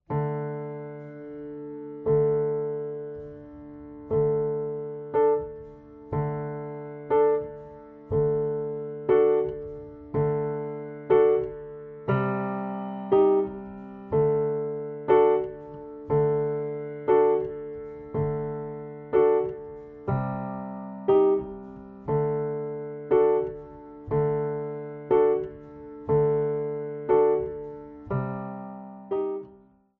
Besetzung: Violoncello